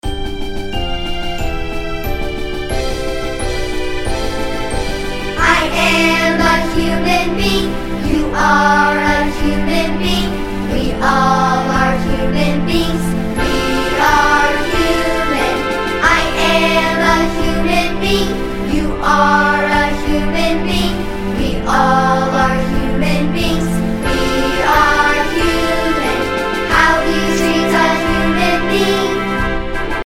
_ The full-length music track with vocals.